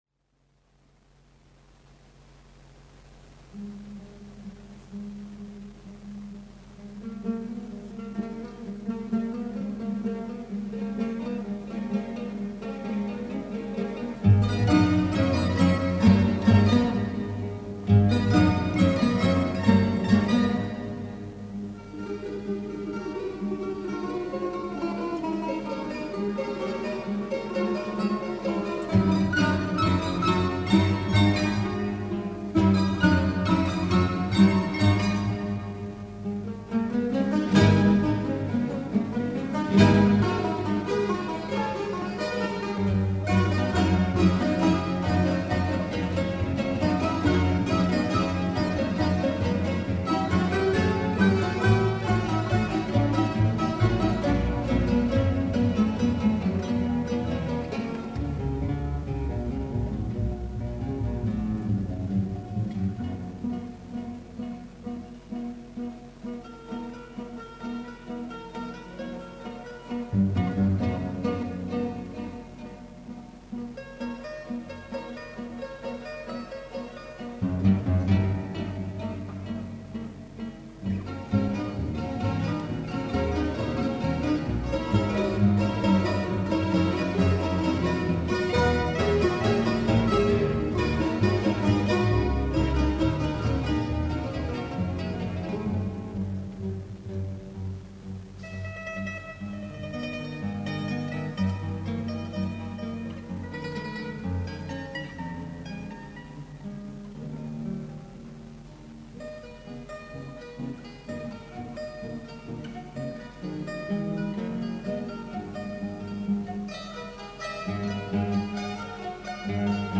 • 18 Aprile 2009 ore 21,00 Bagnatica - Chiesa Parrocchiale
mp3 Live